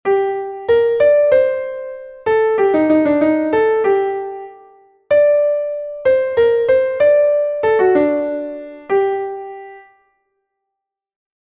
Neste caso, a velocidade ou pulso varía pero as figuras teñen a mesma duración.
O compás de 6/8 irá máis lento de pulso porque ten máis corcheas.